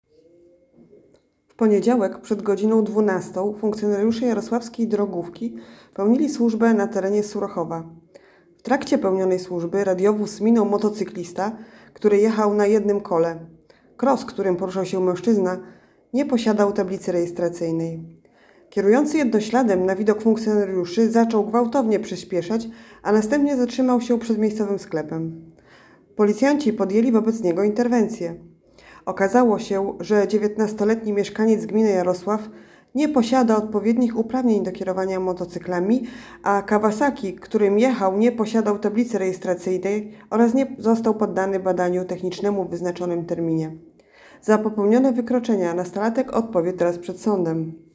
Nagranie audio 19-latek jazdę na jednym kole